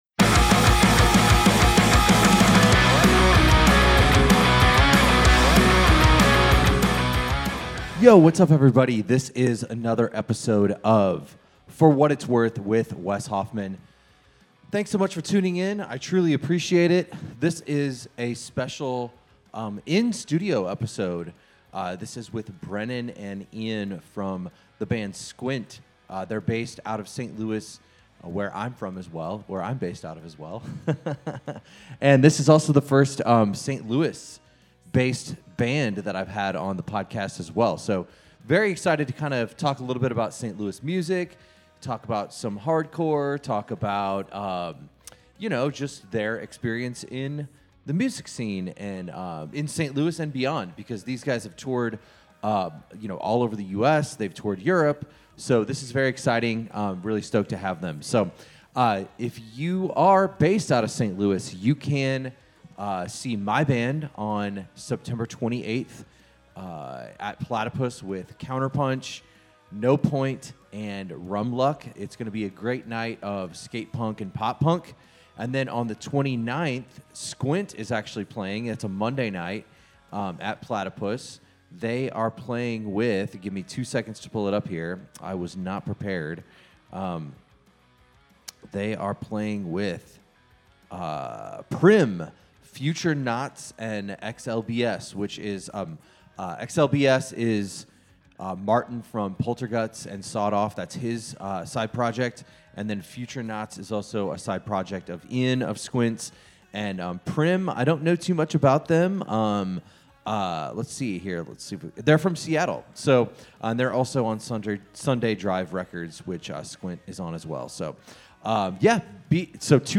This is a special in-studio episode with St. Louis-based band, Squint. This was a great conversation about hardcore ethos, the origin of Squint, touring, networking, songwriting, the St. Louis music scene and so much more.